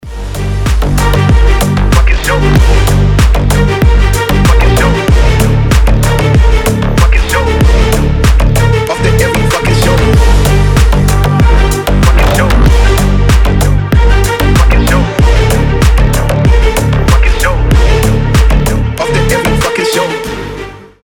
• Качество: 320, Stereo
скрипка
басы
Bass House
G-House
ремиксы